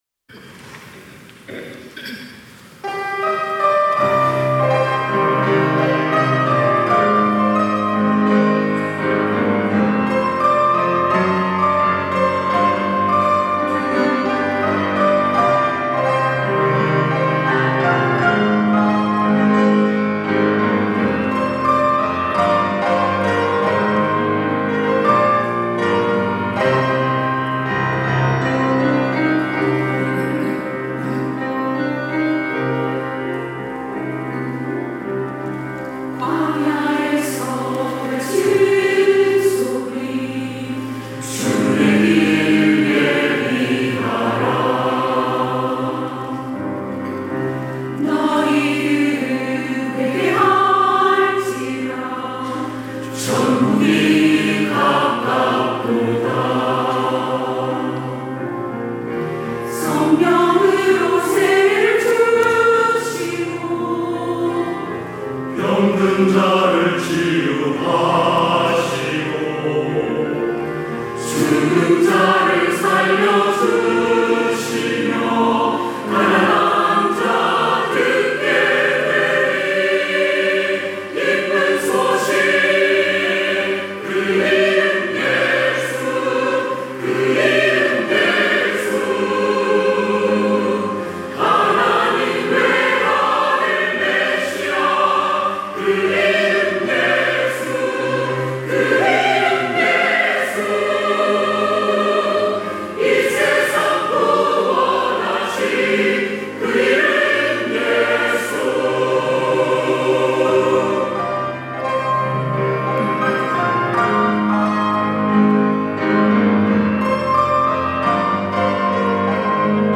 찬양대